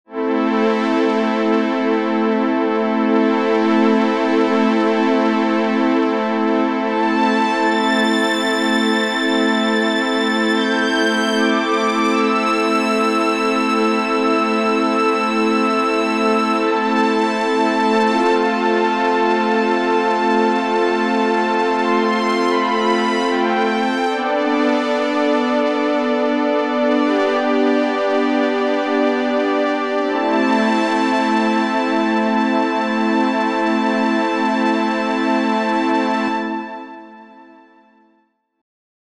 Ein analog anmutendes String Pad: